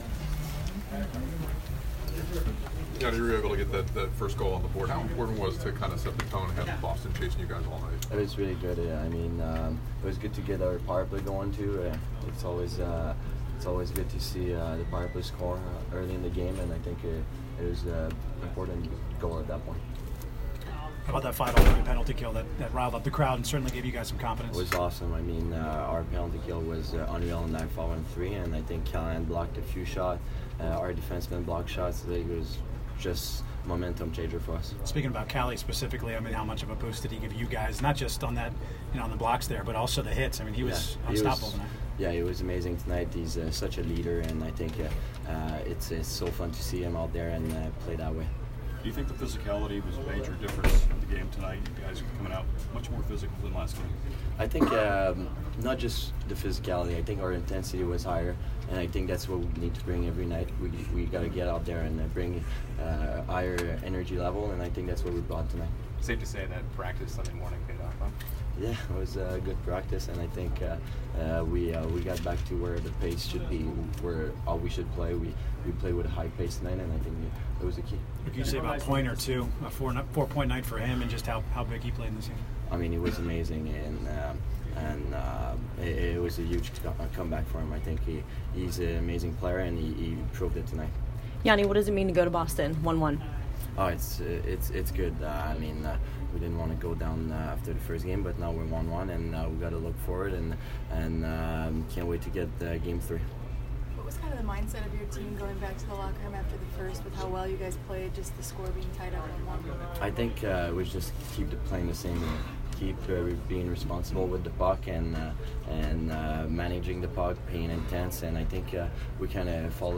Yanni Gourde post-game 4/30